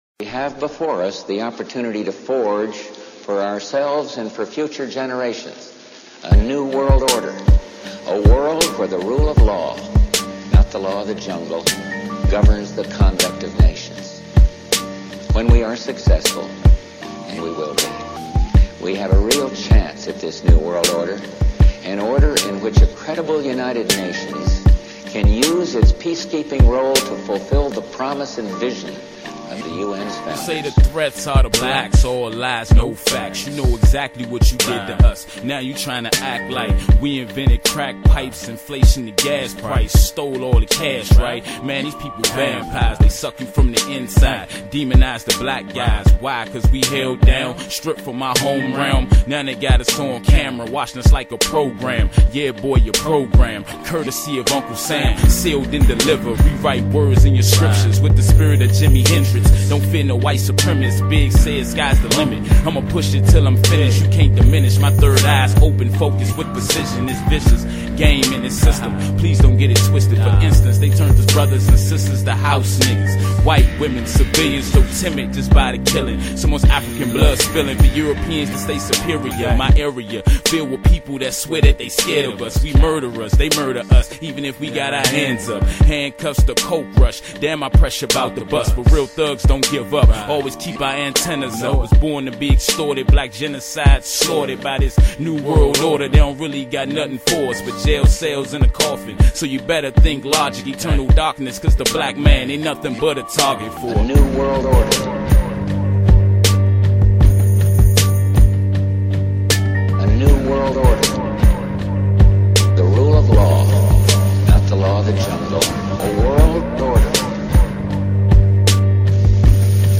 The lyrics from this song I recorded with my friend came to mind.
I added those tambourines later and VSTi guitar bass in the chorus.